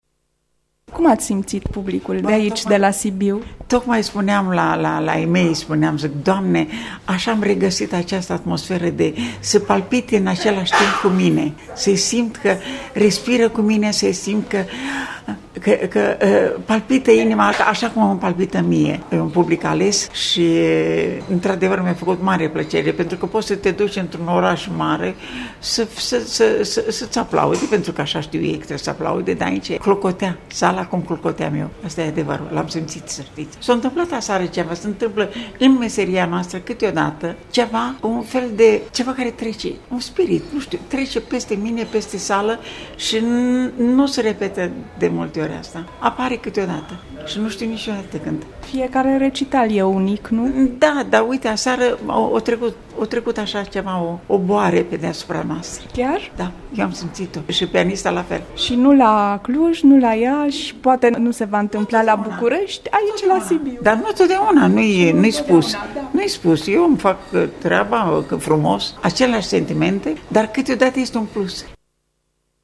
Sala „Thalia”din Sibiu a găzduit, în cadrul Festivalului de Operă ( în desfăşurare până duminică- 4 octombrie), recitalul extraordinar al mezzosopranei Viorica Cortez.